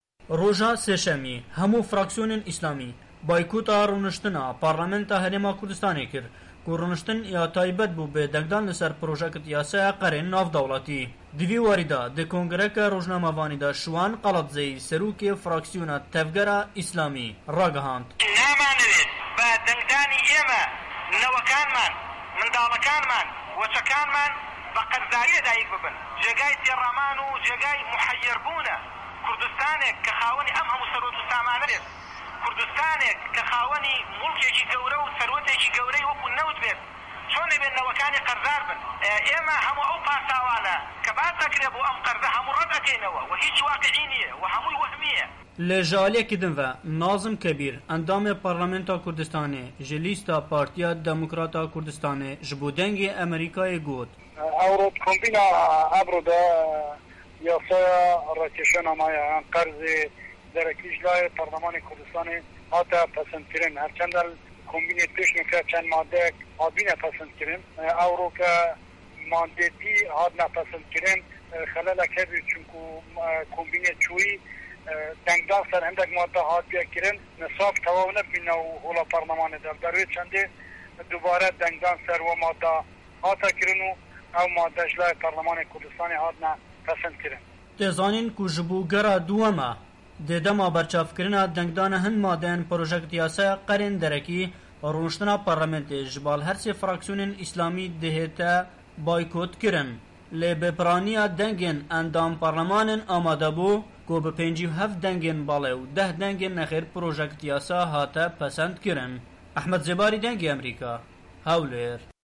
راپۆرتی